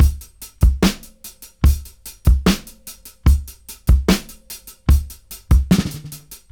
73-DRY-07.wav